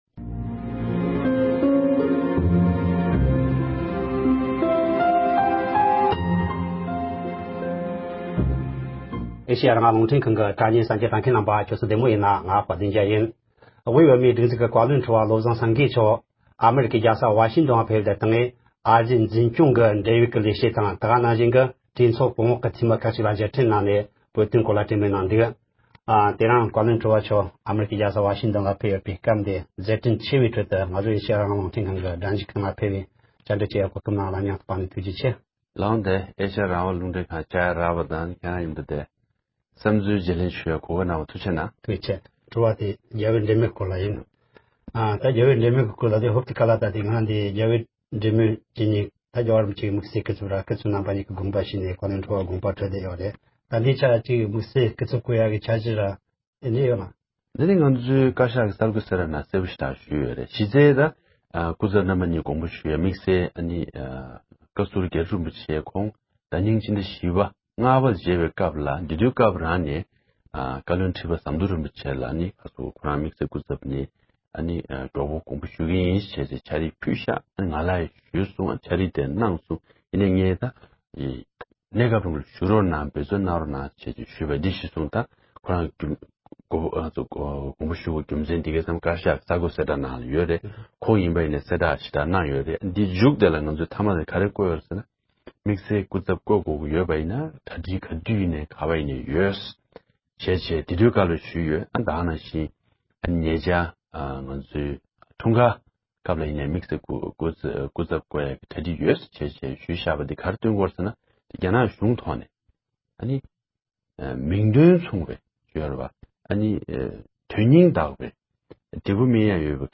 རང་ལུས་མེ་སྲེག་གི་ལས་འགུལ་སྐོར་ལ་བཀའ་བློན་ཁྲི་བ་མཆོག་ལ་བཅར་འདྲི་ཞུས་པ།